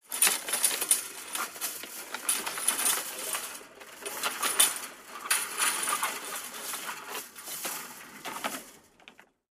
fo_chainfence_rustle_01_hpx
Chain link fence is rattled. Rattle, Chain Link Fence Metallic, Chain Link Fence